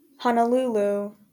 Honolulu (/ˌhɒnəˈll/
HON-ə-LOO-loo;[8] Hawaiian: [honoˈlulu]) is the capital and most populous city of the U.S. state of Hawaii, located in the Pacific Ocean.